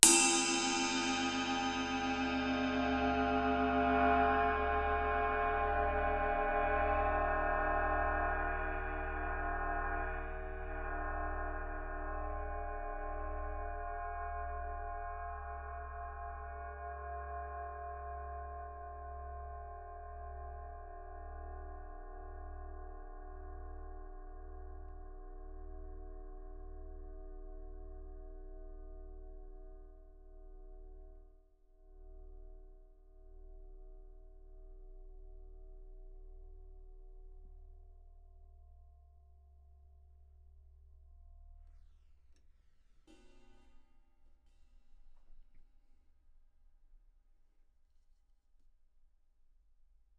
You then record a source and vary the mic position as the source sound decays, allowing you to pick up more of the detail in the decay of the sound.
I also experimented with moving the mic around the cymbal surface to capture different harmonics.
flying-cymbal-3.wav